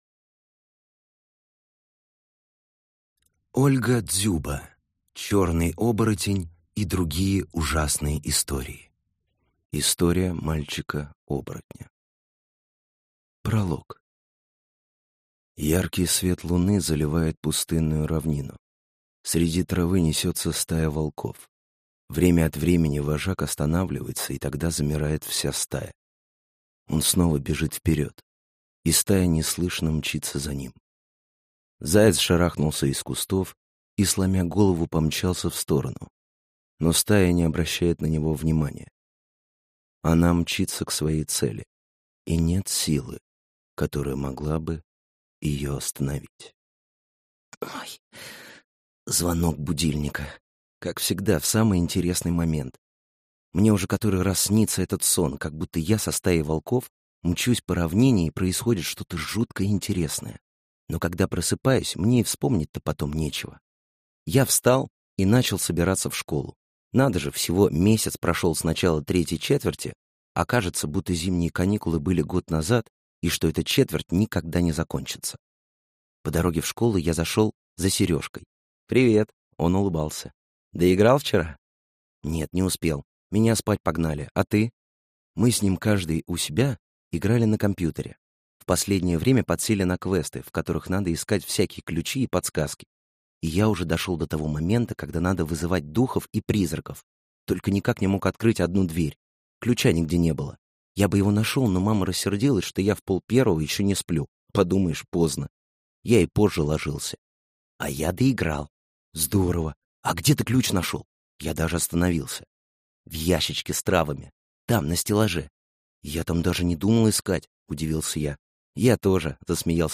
Аудиокнига Черный Оборотень и другие ужасные истории | Библиотека аудиокниг